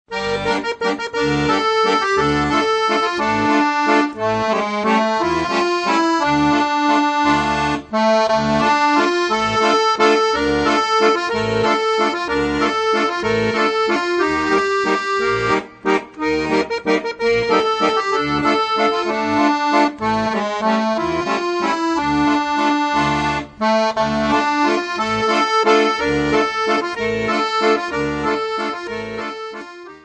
Besetzung: Akkordeon